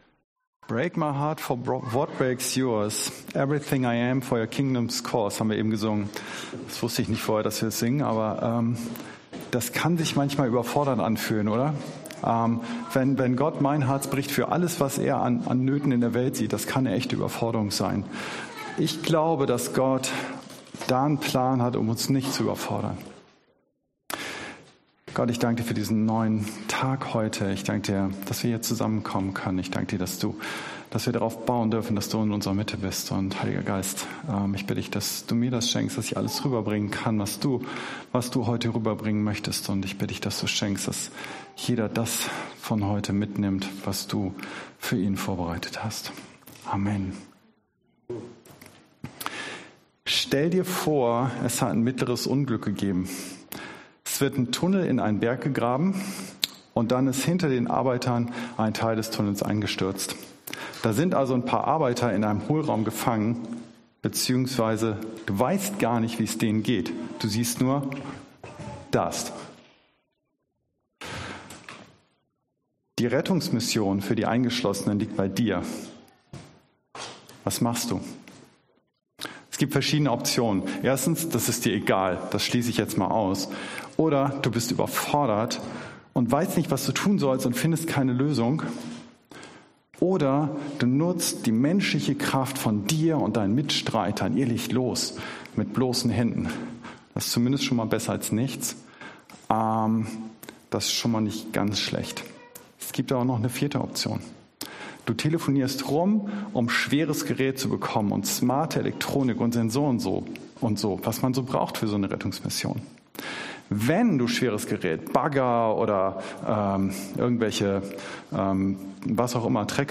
Entfache sie neu in dir Dienstart: Predigt Themen: Geistesgaben , Heiliger Geist « Geistesgaben kennenlernen Gemeinschaft mit Jesus